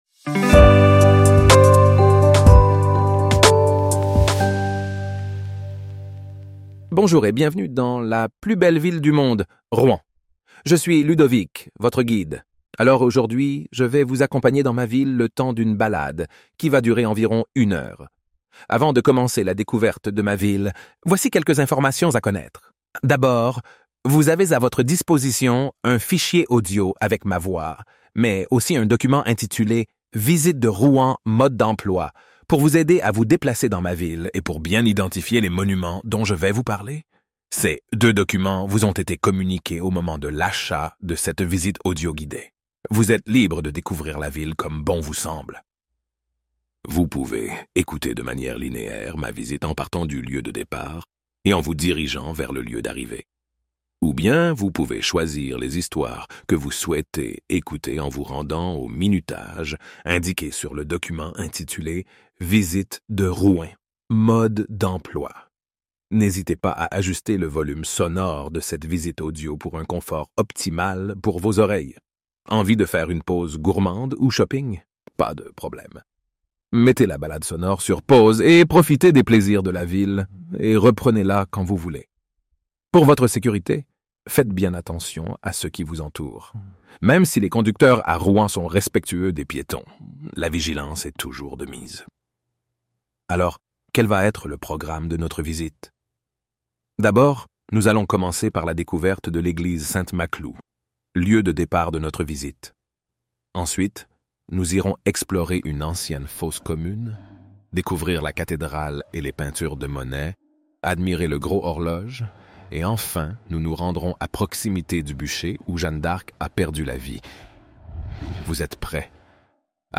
Visite à pied de Rouen avec un guide touristique local
Téléchargez votre visite audio guidée et votre livret d’instructions